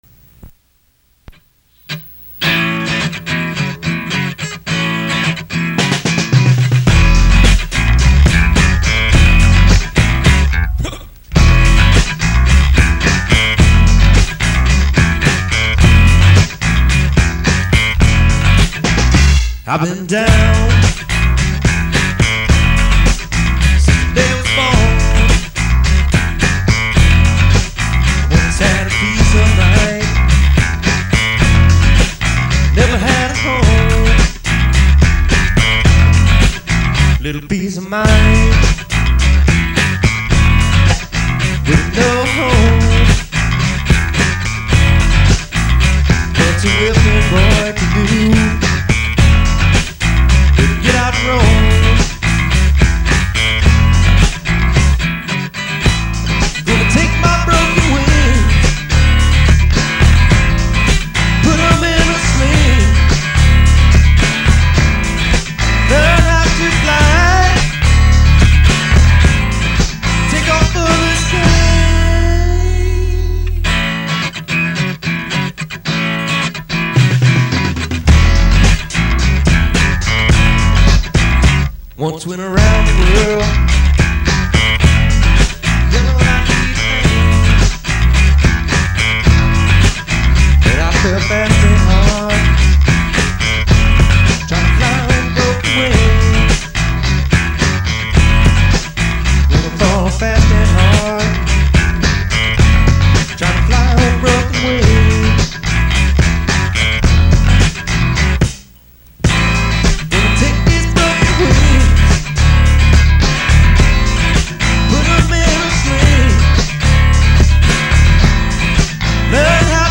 Country
Folk
Country-rock